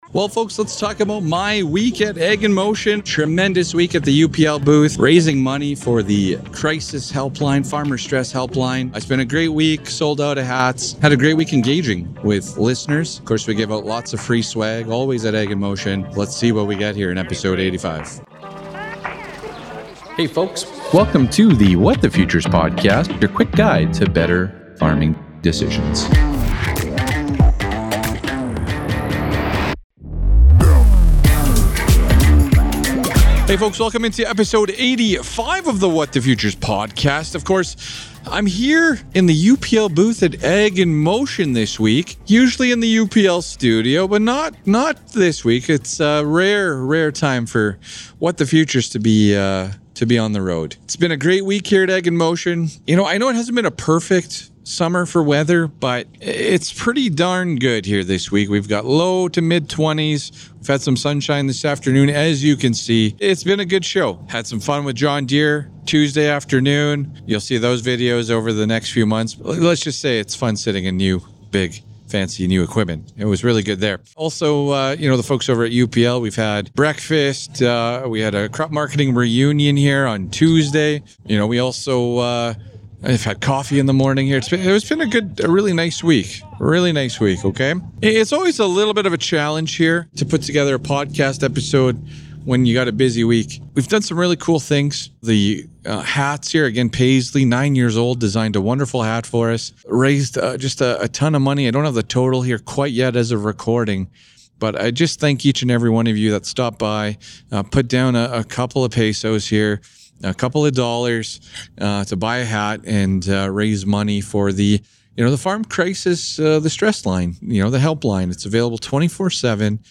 Recorded at Ag in Motion, What the Futures tackles the BIG Canadian crop marketing questions: